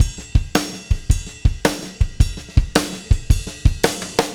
RKSH RIDE -R.wav